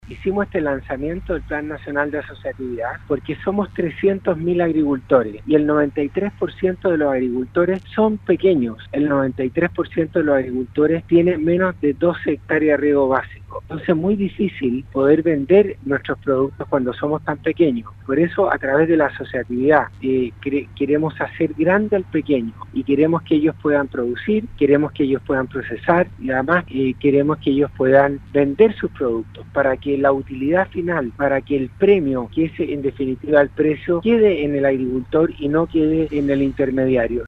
El Ministro de Agricultura, Antonio Walker, en conversación con Radio SAGO, recordó que el Plan Nacional de Asociatividad tiene por objetivo lograr el crecimiento del 93% de 300 mil agricultores que son pequeños, con no más de 12 hectáreas.